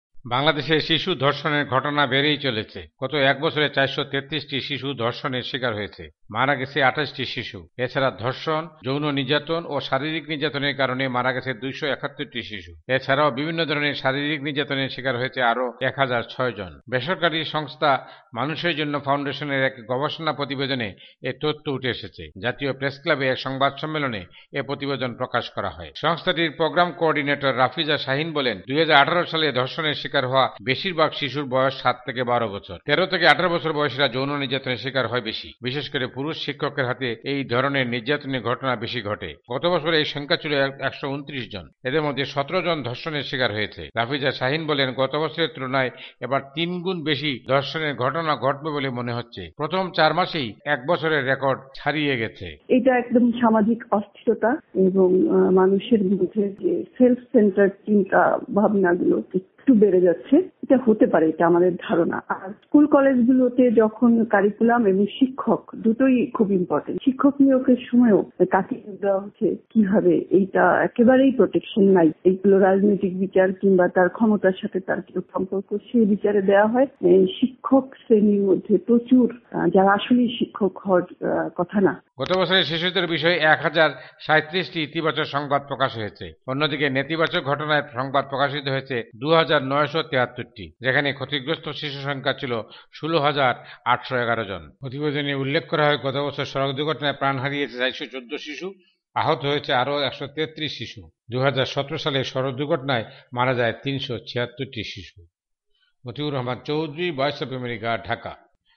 ঢাকা থেকে